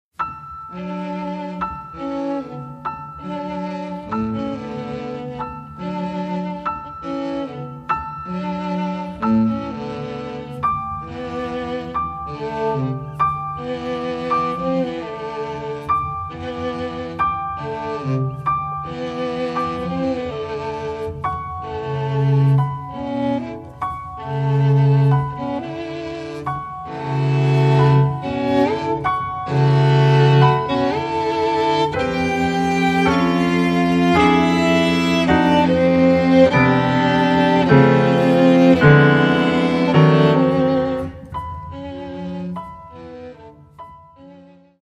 Nahráno v zimě 2003/2004 ve studiu PIVOX